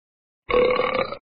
Burp Meme Effect Sound sound effects free download
Burp - Meme Effect Sound